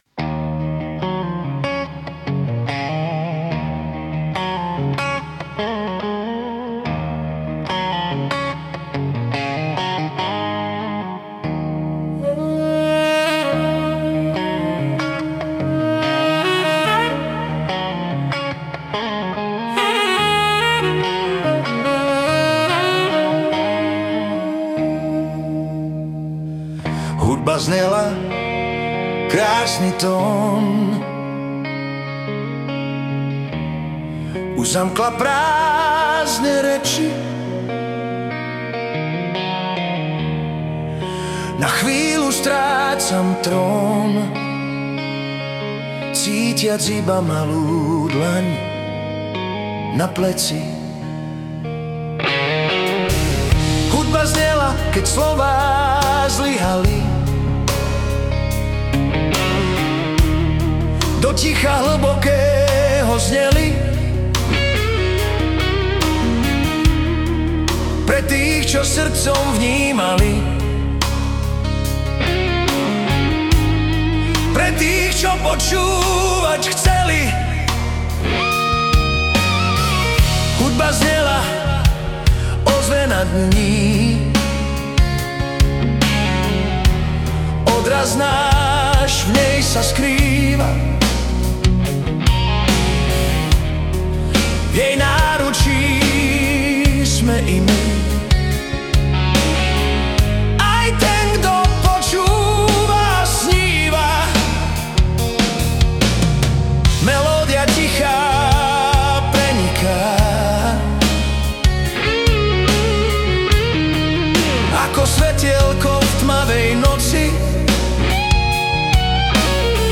Hudba a spev AI
Balady, romance » Romantické